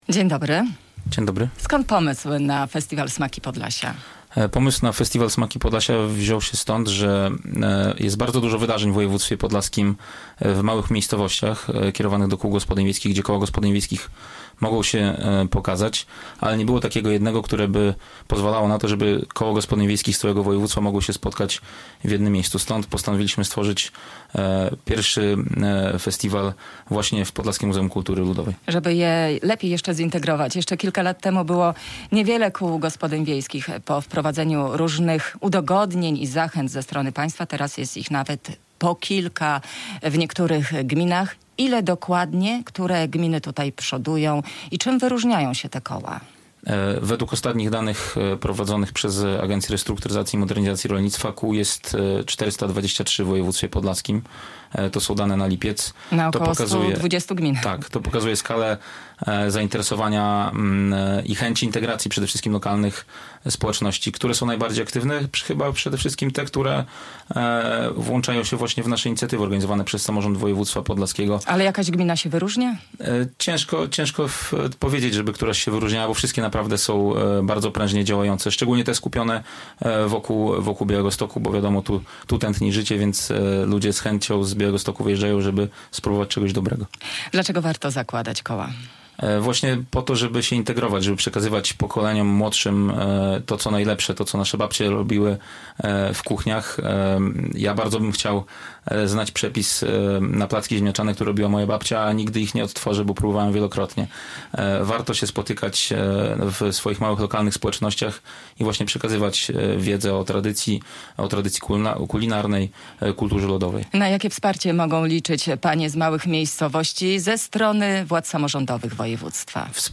Radio Białystok | Gość | Sebastian Łukaszewicz [wideo] - wicemarszałek województwa podlaskiego